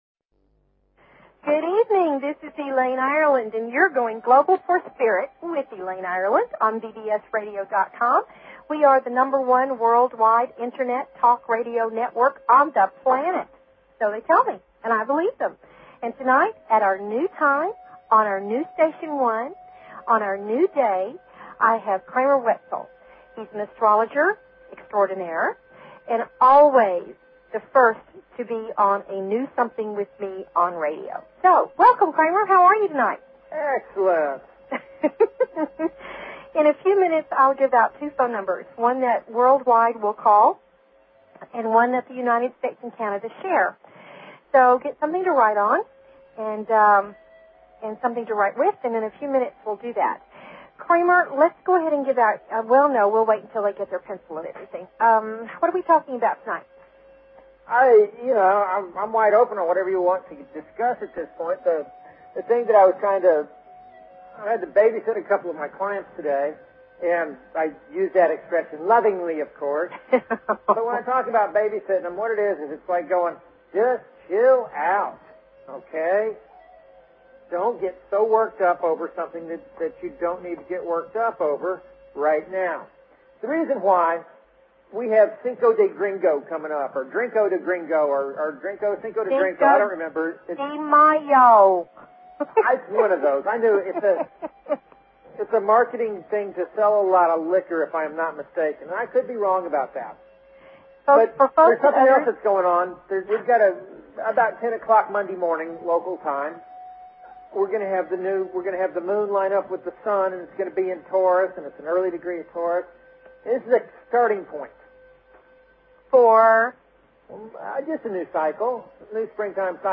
Talk Show Episode, Audio Podcast, Going_Global_for_Spirit and Courtesy of BBS Radio on , show guests , about , categorized as
They invite you to call in with your questions and comments about everything metaphysical and spiritual!"